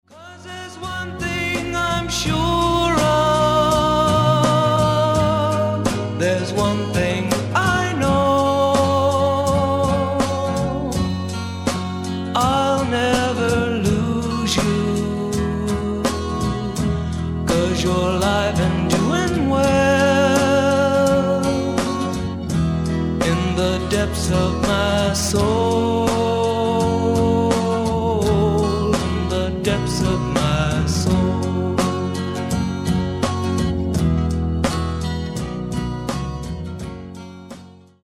SOFT ROCK / PSYCHEDERIC POP